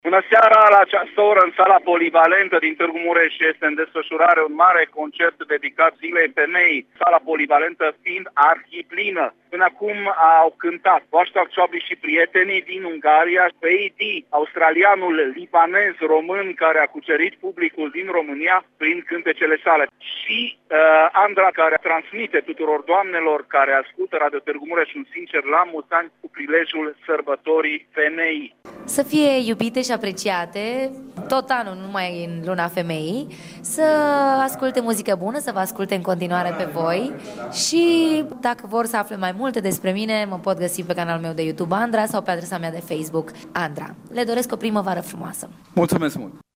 Concert dedicat Zilei Femeii, la Sala Polivalentă din Tg.Mureș